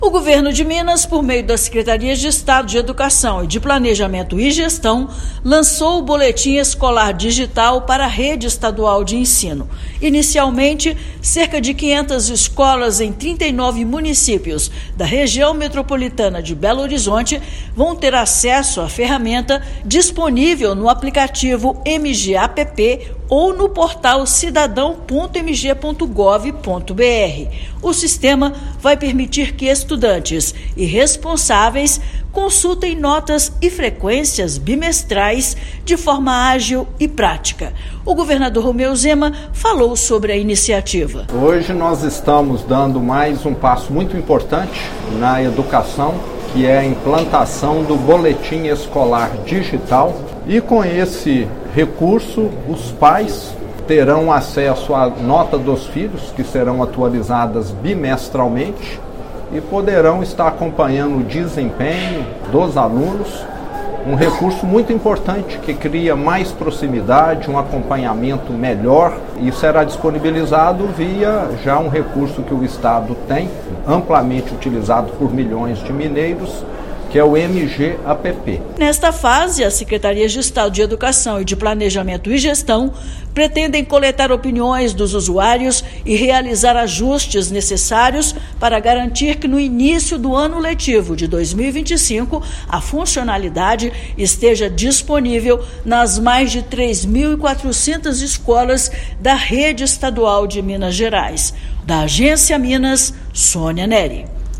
Cerca de 300 mil alunos da Região Metropolitana de Belo Horizonte (RMBH) e responsáveis vão acessar frequência e notas pelo celular; expectativa é a de que, no ano que vem, todas as escolas tenham acesso. Ouça matéria de rádio.